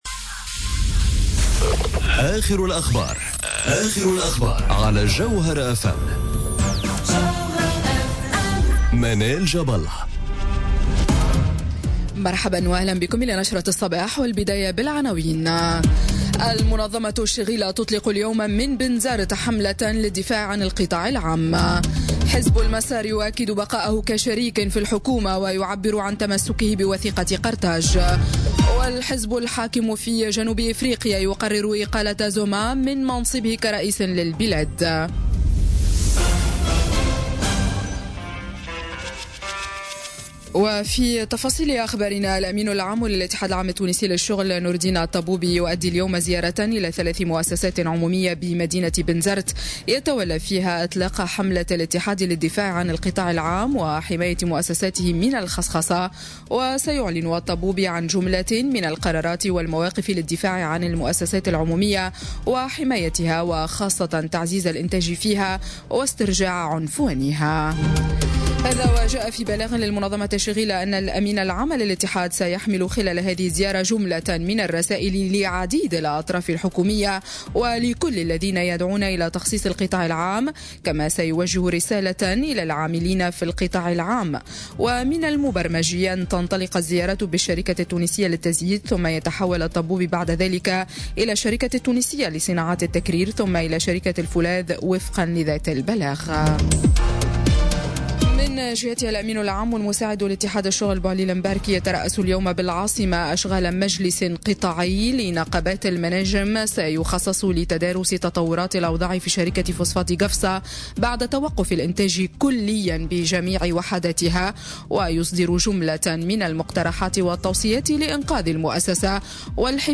نشرة أخبار السابعة صباحا ليوم الثلاثاء 13 فيفري 2018